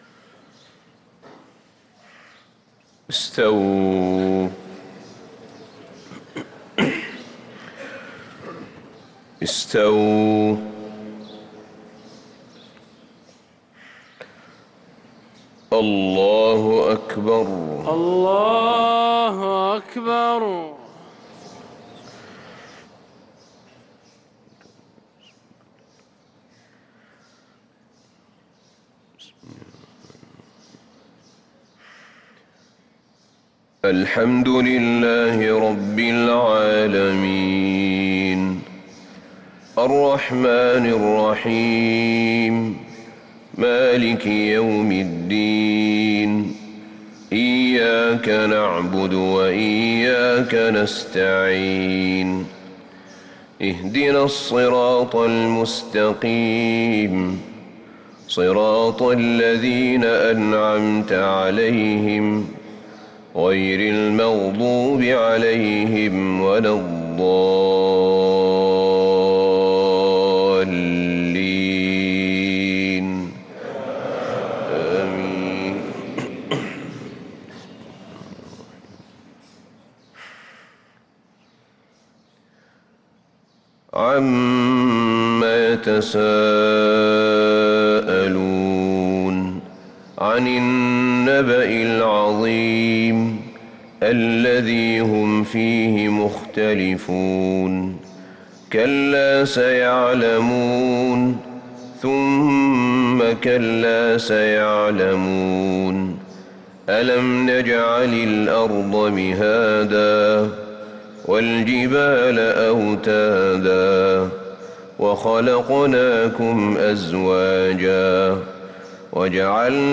صلاة الفجر للقارئ أحمد بن طالب حميد 20 شعبان 1444 هـ
تِلَاوَات الْحَرَمَيْن .